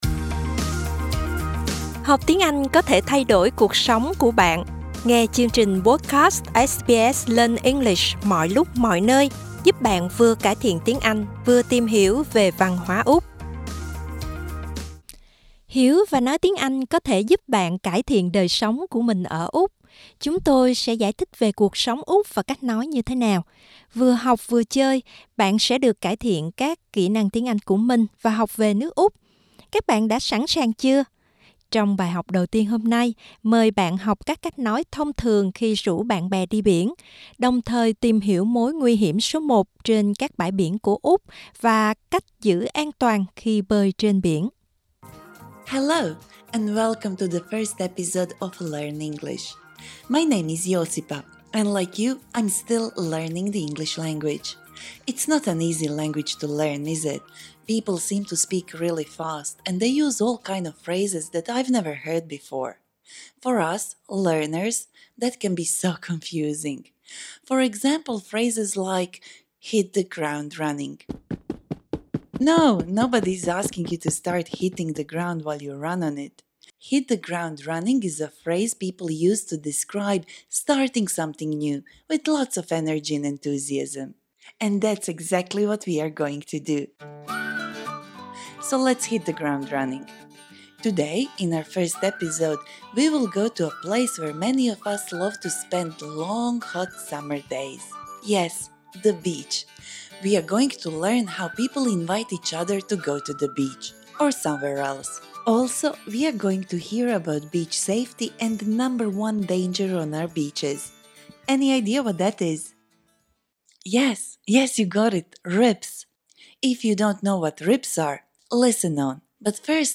Learning notes Lesson language objective: How to invite a friend to go somewhere Ways to ask a friend to go to the beach (or somewhere else): Do you wanna hit the beach?